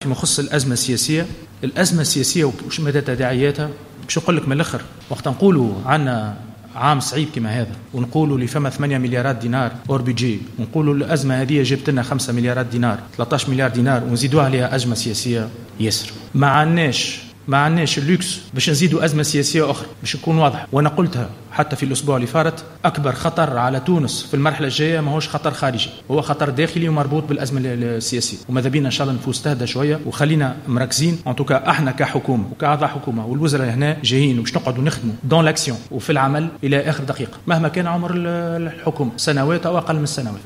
حذر وزير المالية نزار يعيش في تصريح لمراسل الجوهرة "اف ام" على هامش ندوة صحفية بمقر رئاسة الحكومة في القصبة اليوممن تداعيات الأزمة السياسية على البلاد التي تشهد صعوبات اقتصادية وإضافة 13 مليار دينار للميزانية.